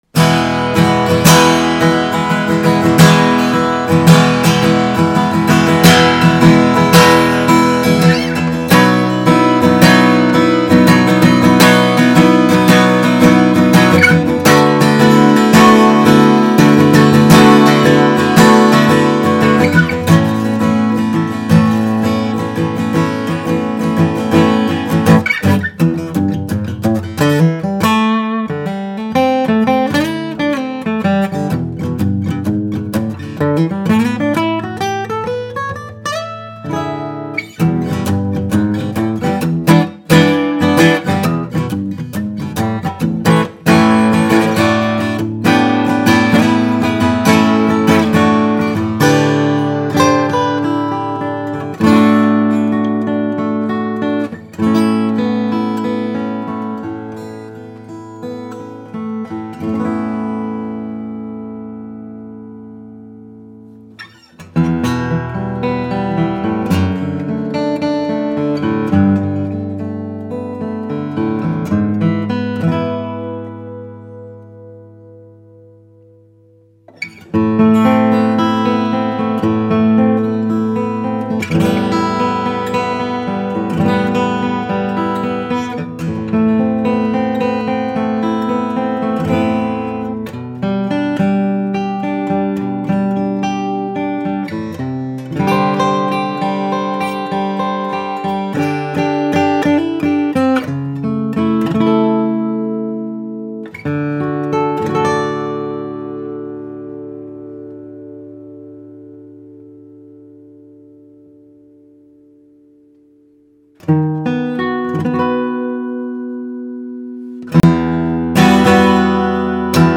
She sounds like a young vintage right out of the case.
Whether you’re playing in a studio, on stage, or at home, this baby sings with a powerful low-end, shimmering highs, and a beautifully open midrange.
• Top Material: Adirondack Red Spruce
• Back & Sides Material: East Indian Rosewood